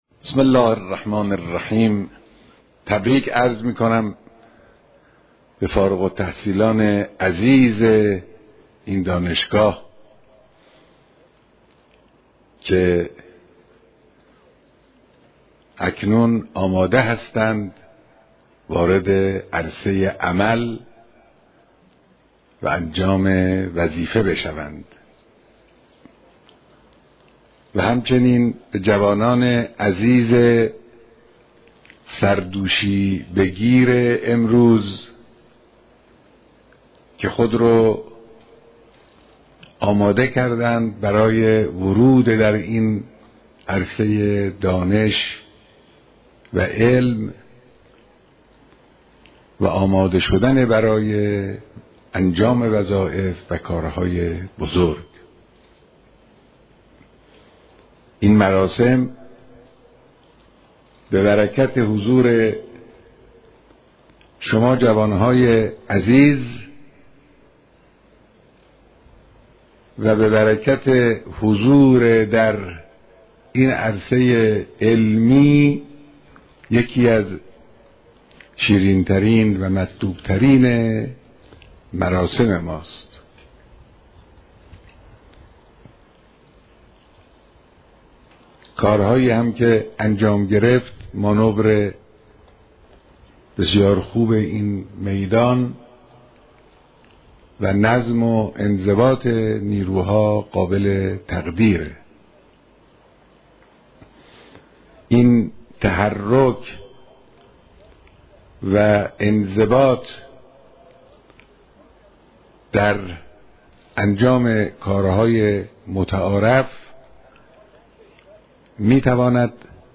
مراسم دانش آموختگی دانشجویان دانشگاه علوم انتظامی
بيانات در دانشگاه علوم انتظامى‌